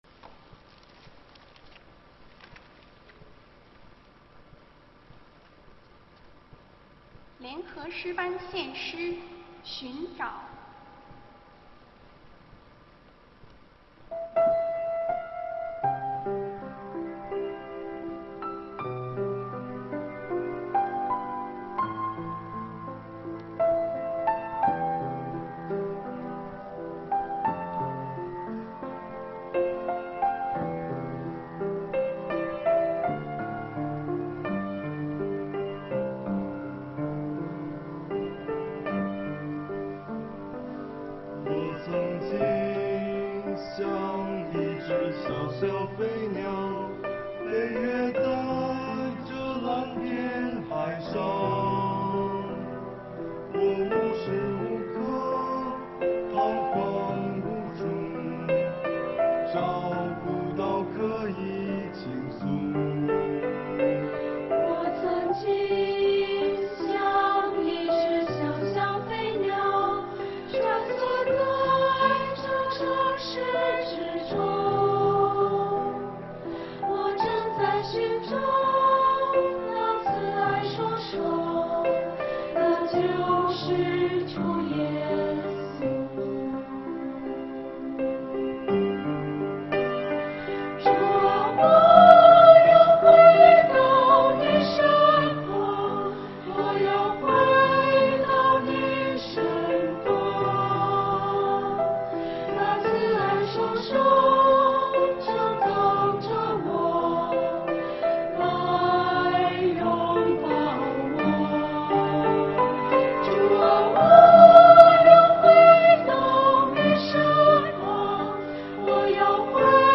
团契名称: 青年诗班 新闻分类: 诗班献诗 音频: 下载证道音频 (如果无法下载请右键点击链接选择"另存为") 视频: 下载此视频 (如果无法下载请右键点击链接选择"另存为")